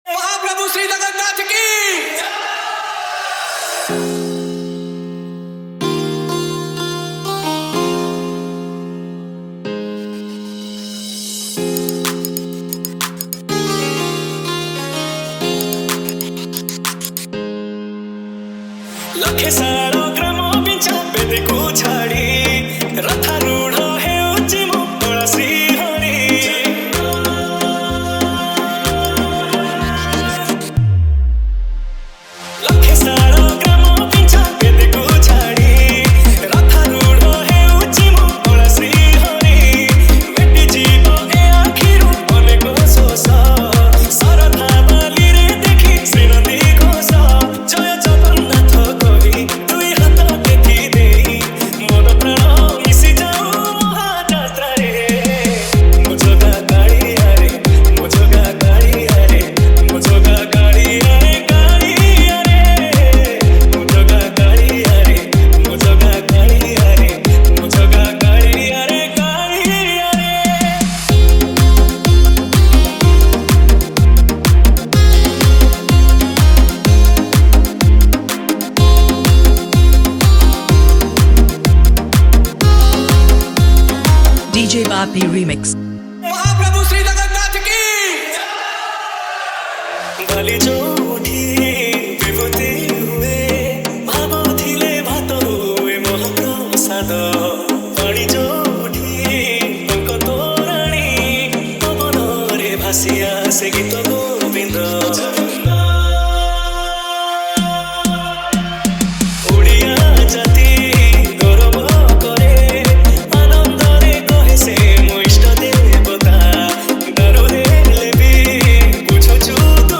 Category:  Odia Bhajan Dj 2024